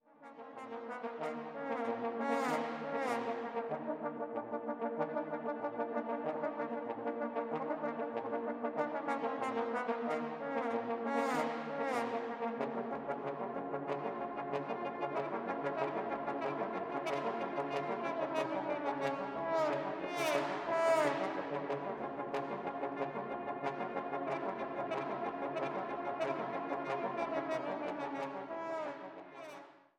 Posaunenquartett